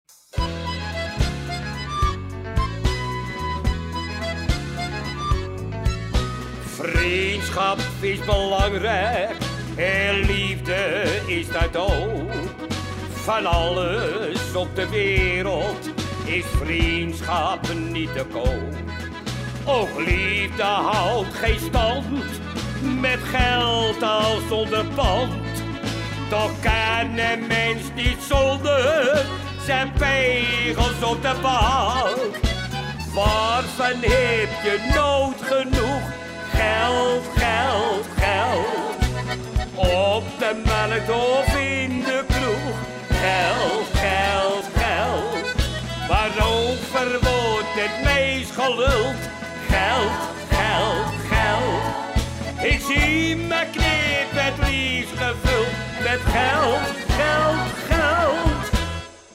Uiteraard is dit geen CD-kwaliteit.